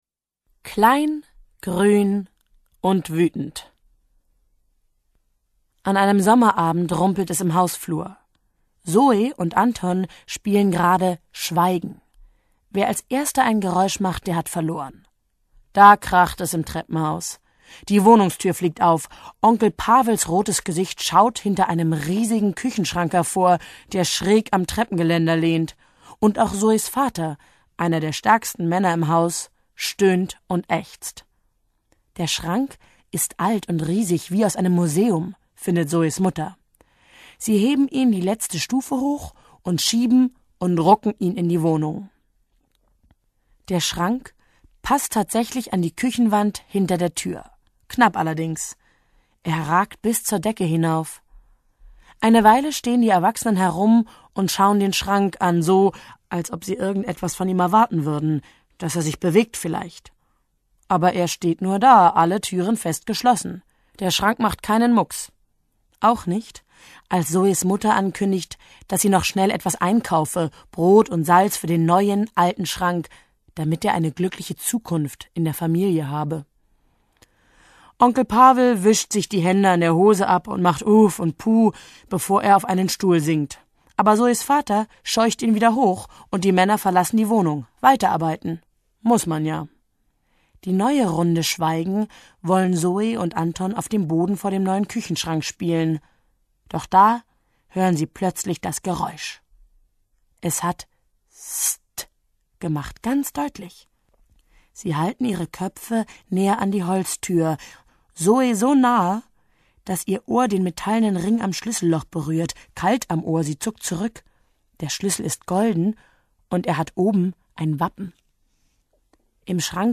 Schlagworte Freunde • Freundschaft • grinsen • Hofstaat • Hörbuch • Hörbuch für Kinder/Jugendliche • Hörbuch für Kinder/Jugendliche (Audio-CD) • Hörbuch; Lesung für Kinder/Jugendliche • Humor • Kichererbse • Kinder • Lachen • Lesung • Prinzessin • Suche • Untertanen • Verschwinden